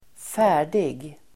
Uttal: [²f'ä:r_dig]